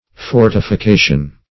Fortification \For`ti*fi*ca"tion\, n. [L. fortificatio : cf. F.